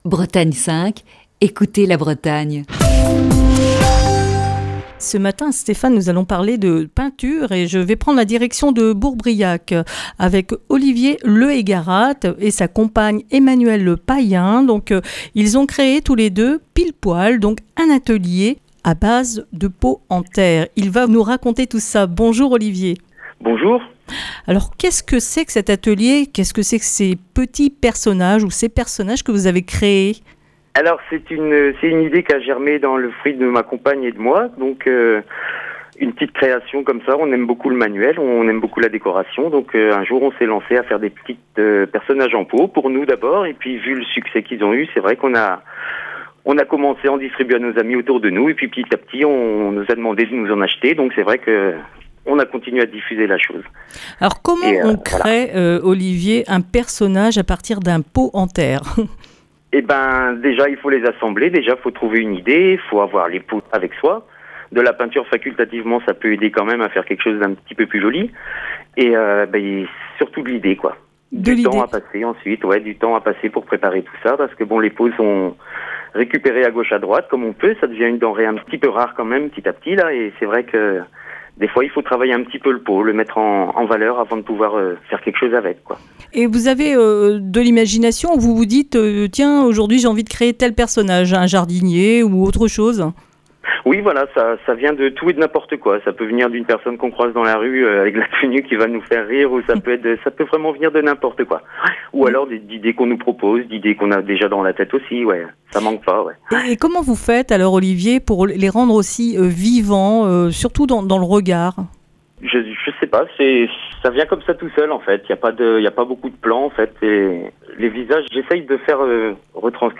Ce lundi dans le Coup de fil du matin